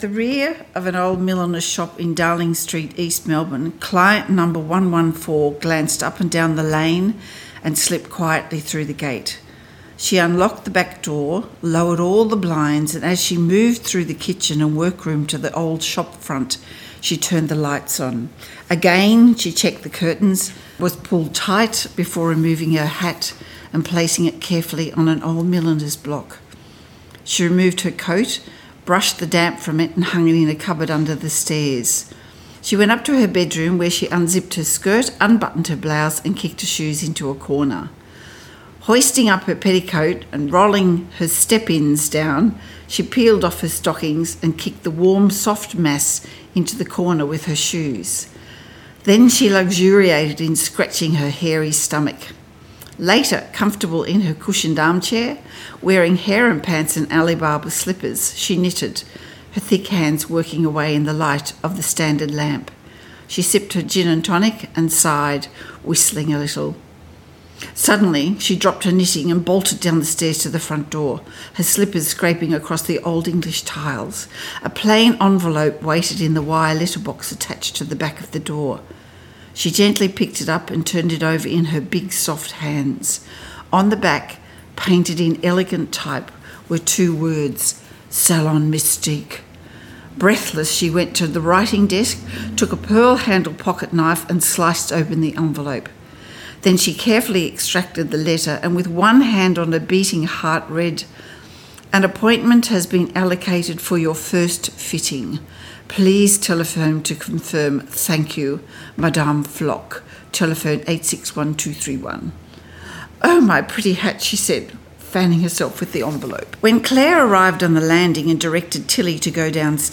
Recorded at BAD Crime Festival Sydney 2022